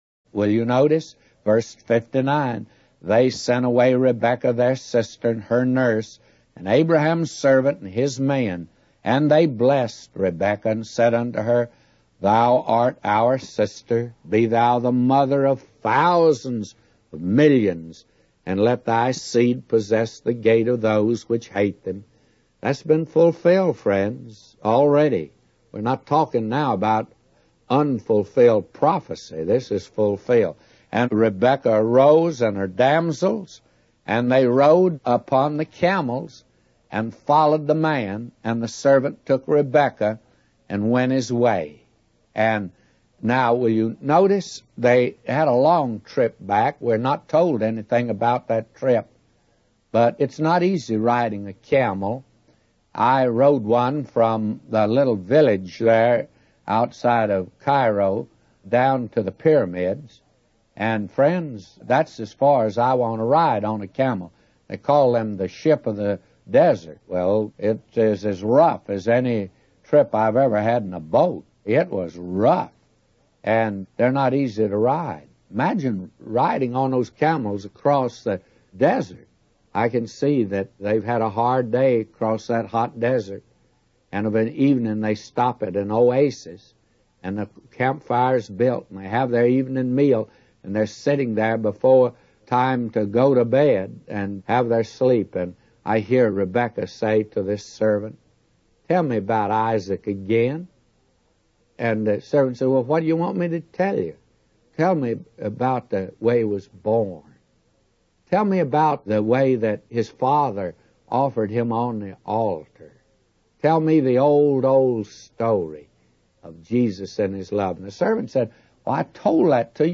The sermon explores the union of Isaac and Rebekah as a picture of Christ's coming and His love for the church.